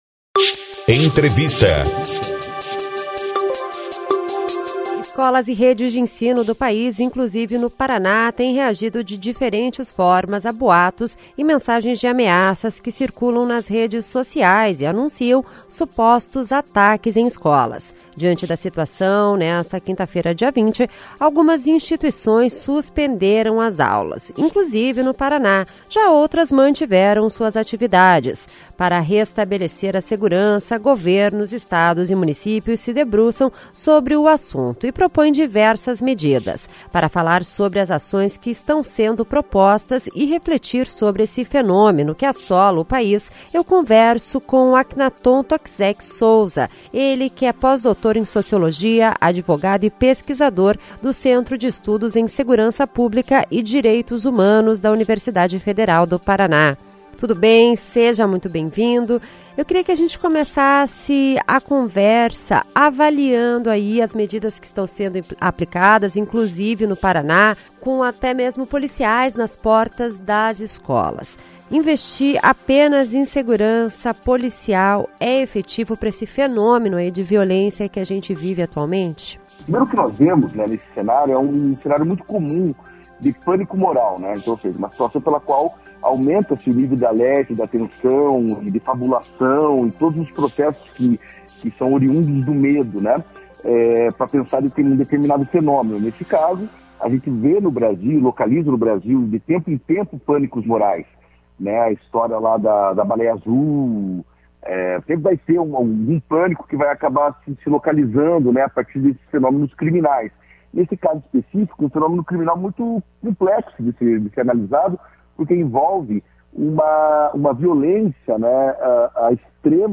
a jornalista